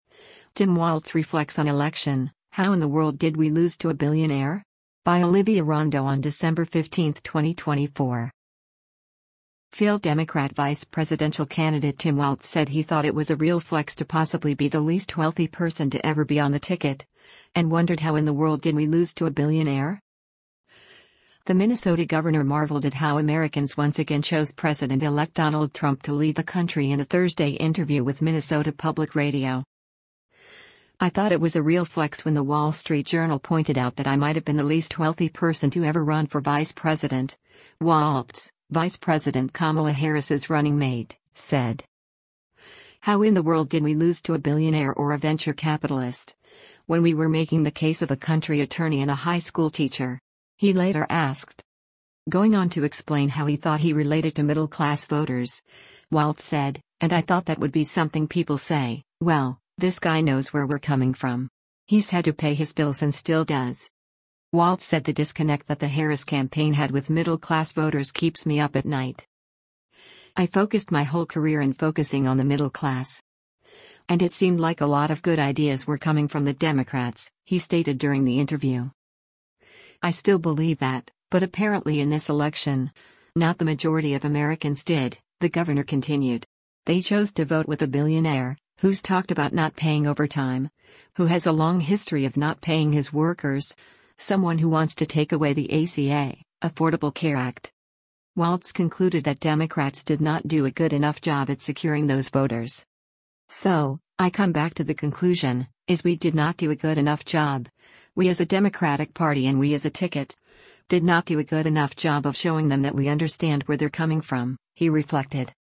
The Minnesota governor marveled at how Americans once again chose President-elect Donald Trump to lead the country in a Thursday interview with Minnesota Public Radio.